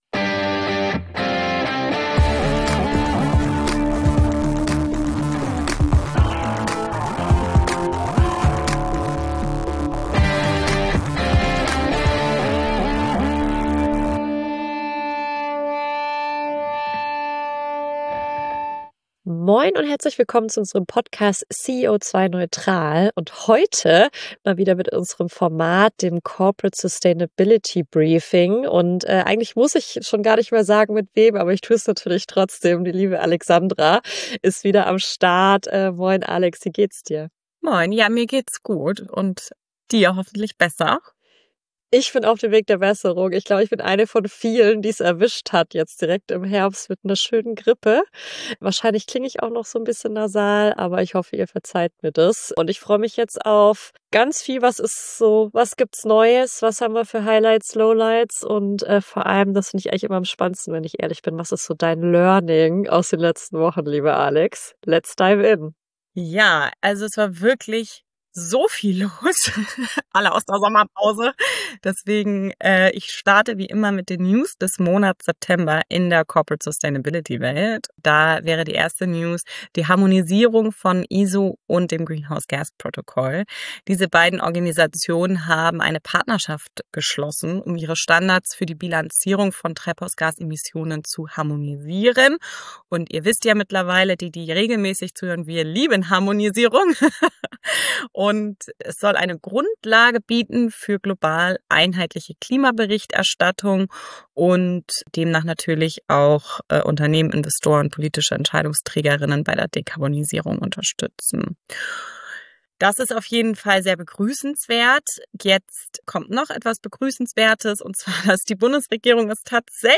CEO2-neutral - Der Interview-Podcast für mehr Nachhaltigkeit im Unternehmen